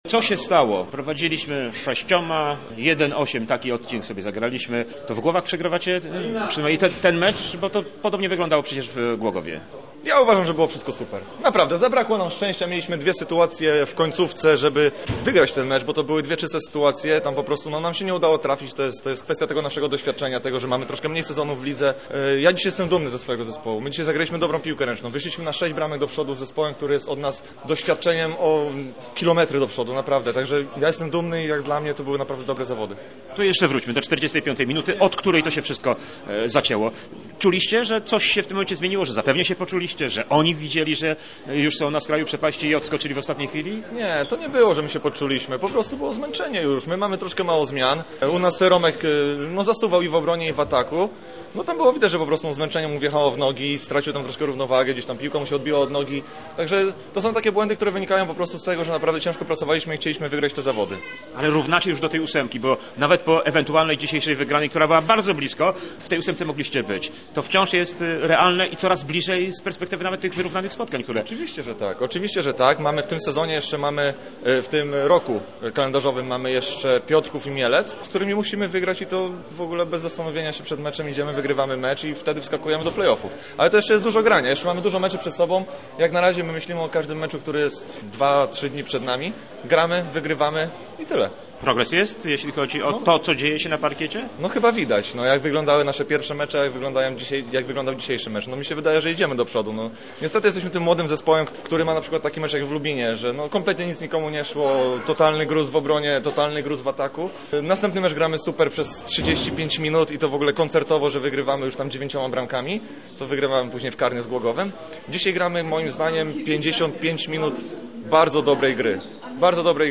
Posłuchaj, co po meczu powiedzieli zawodnicy obu drużyn: